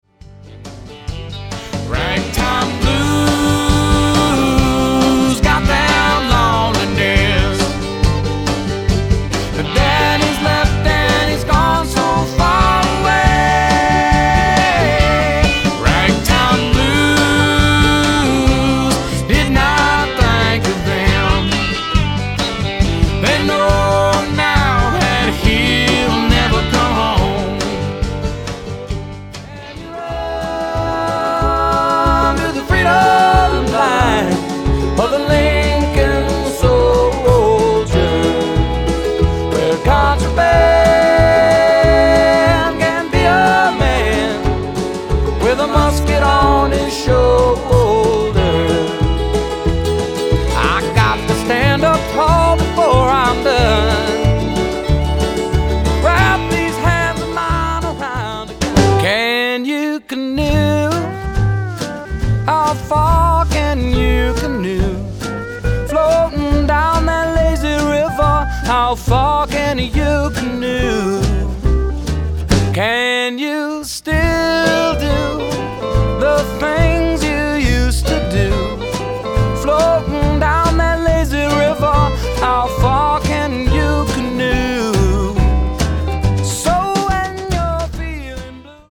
Genre/Style: country, folk, americana